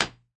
clunk2.ogg